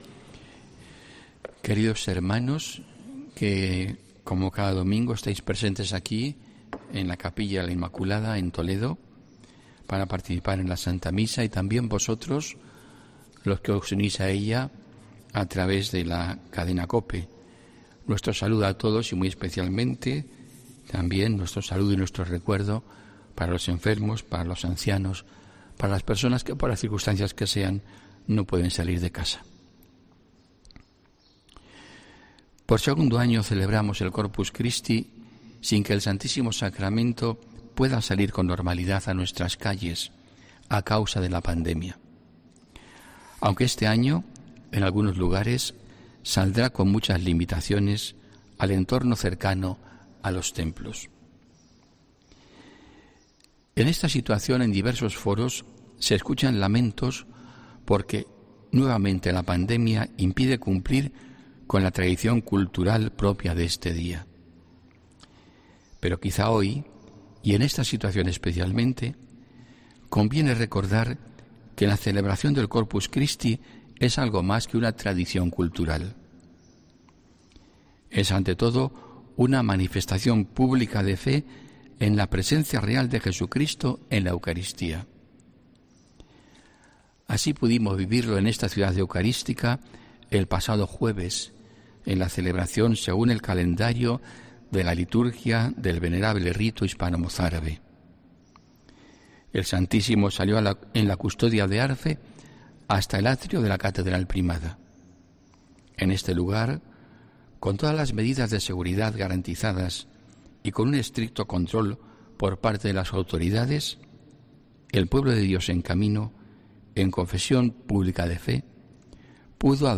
HOMILÍA 6 JUNIO 2021